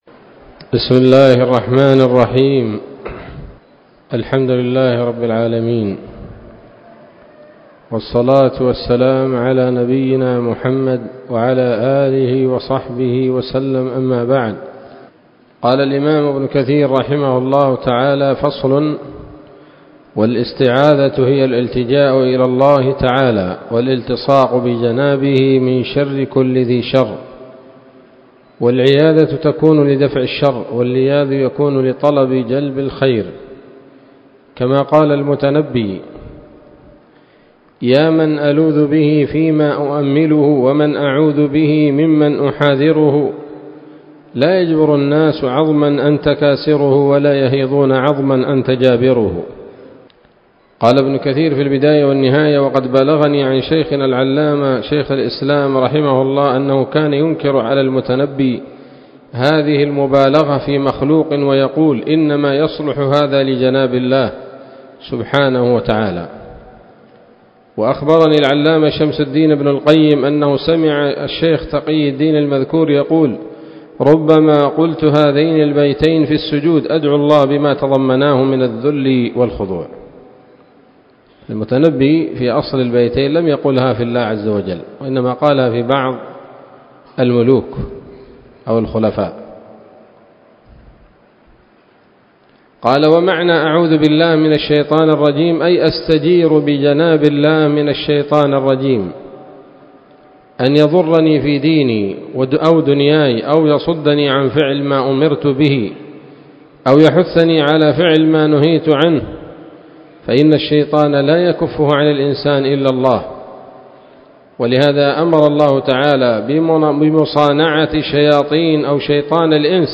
الدرس الثامن من سورة الفاتحة من تفسير ابن كثير رحمه الله تعالى